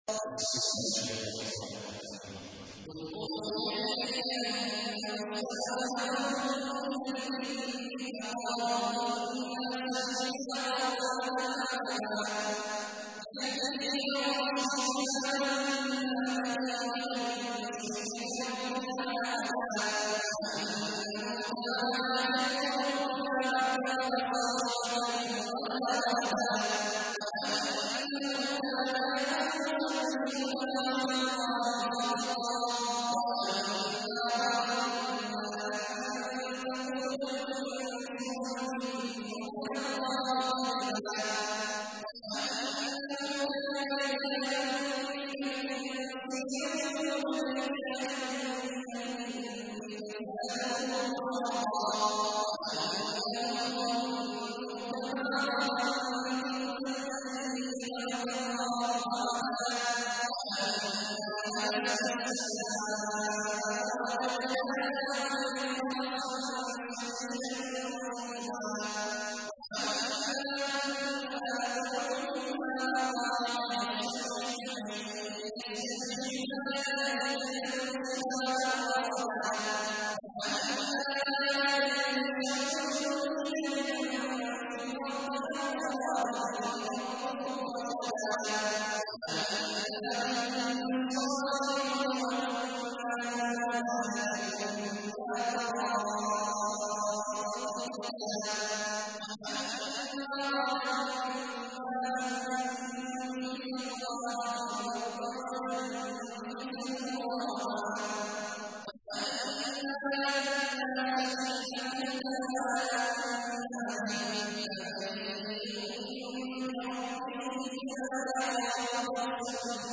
تحميل : 72. سورة الجن / القارئ عبد الله عواد الجهني / القرآن الكريم / موقع يا حسين